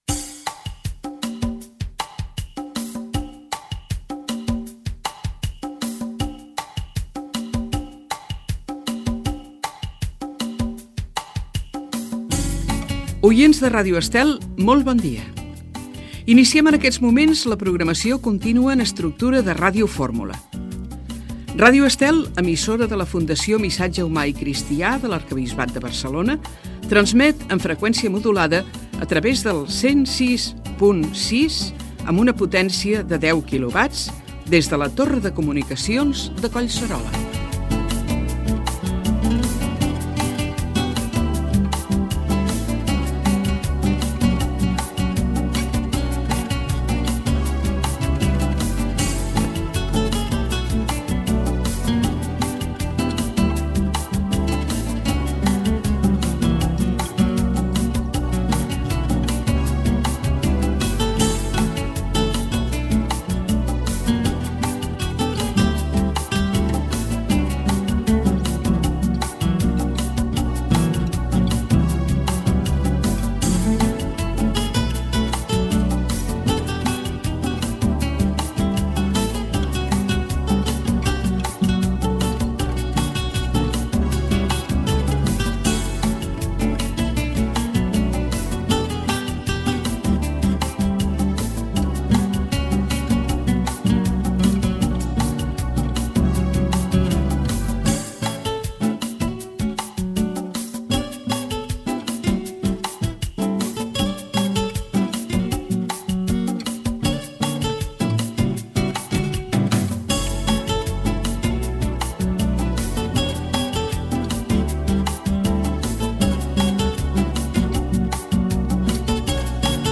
Inici de les emissions a les 5 hores i música identificativa de la ràdio
FM